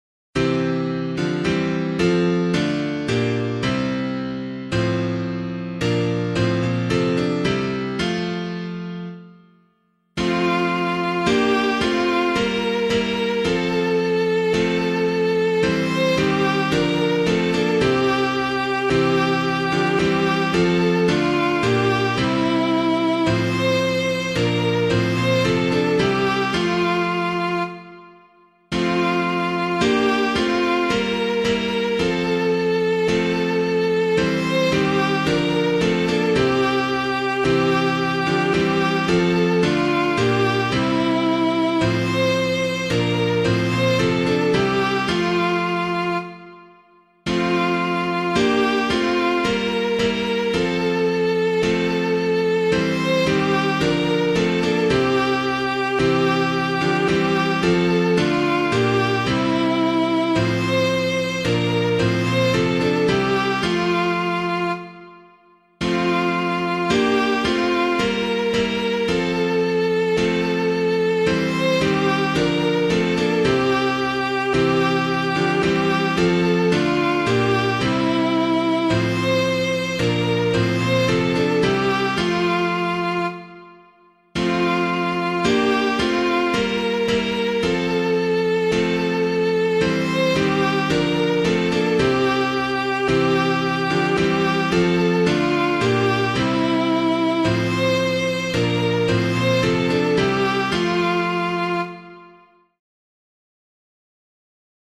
piano
Hail to the Lord Who Comes [Ellerton - PSALM 32] - piano.mp3